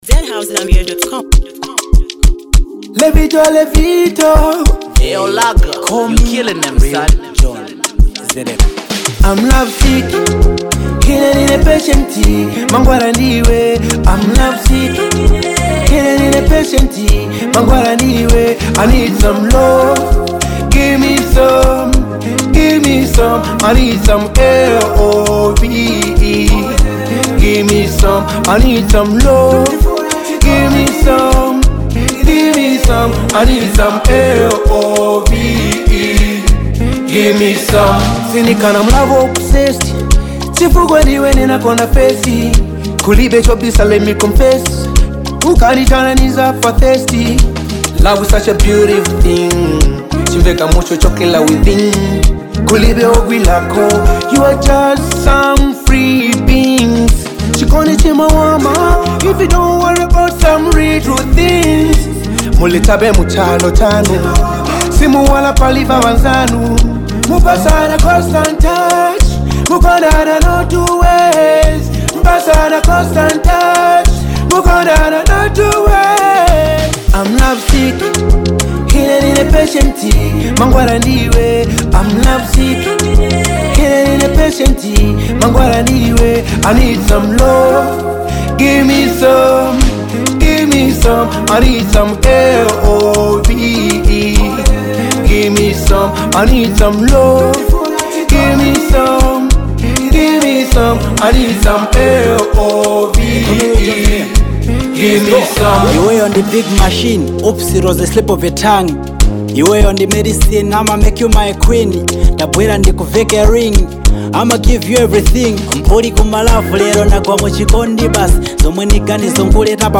smooth, emotional love song